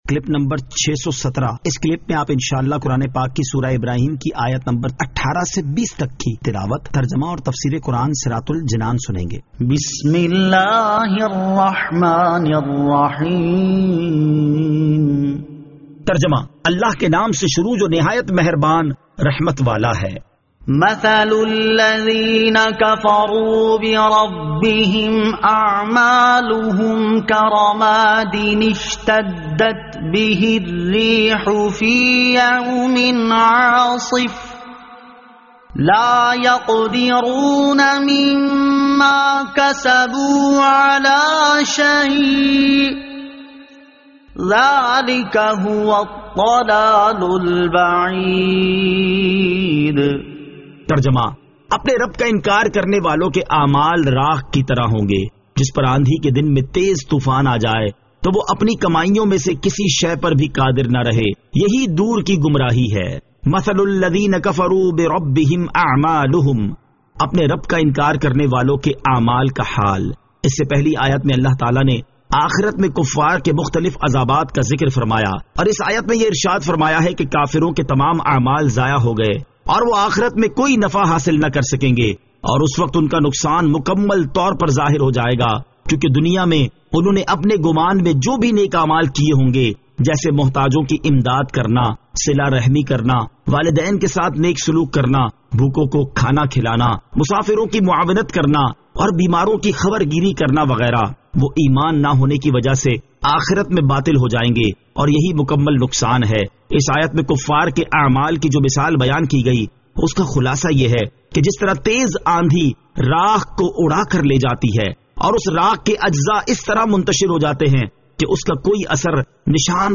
Surah Ibrahim Ayat 18 To 20 Tilawat , Tarjama , Tafseer
2021 MP3 MP4 MP4 Share سُوَّرۃُ ابٗرَاھِیم آیت 18 تا 20 تلاوت ، ترجمہ ، تفسیر ۔